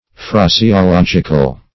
Phraseological \Phra`se*o*log"ic*al\
phraseological.mp3